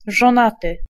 Ääntäminen
IPA : /ˈmæɹ.ɪd/